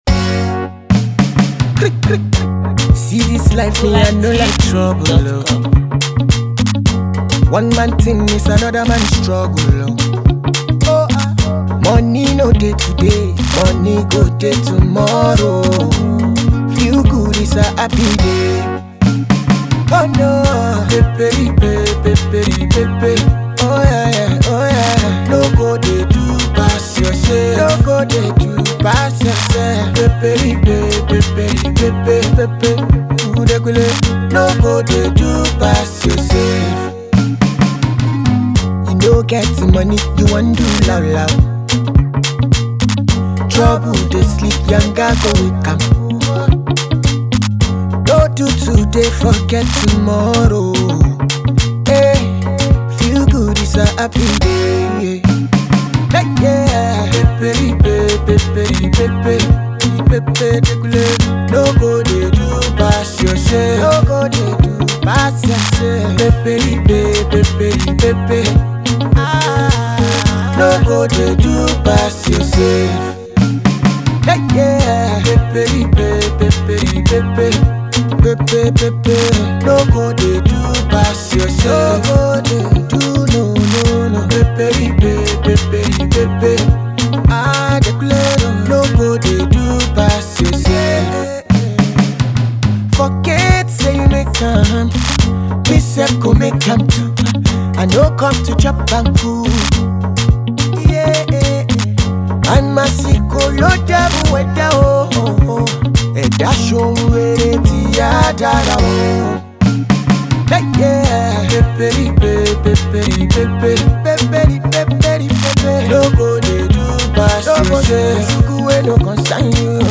A sweet vibe with relatable lyrics